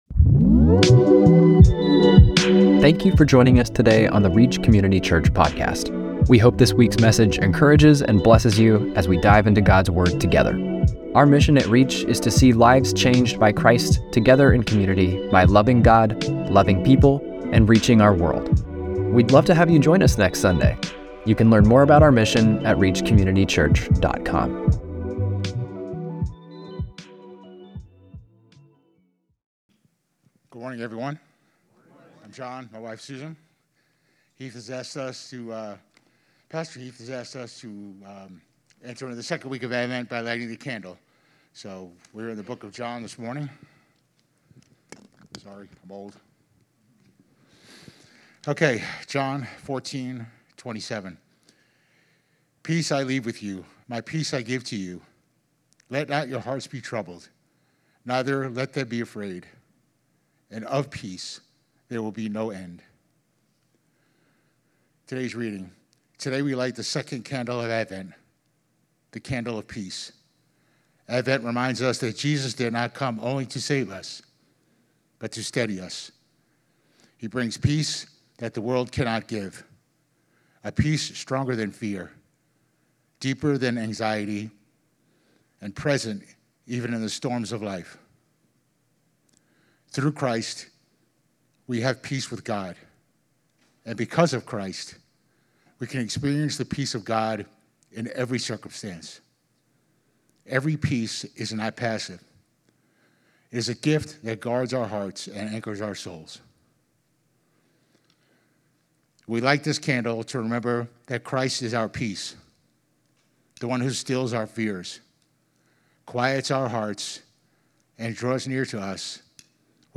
12-7-25-Sermon.mp3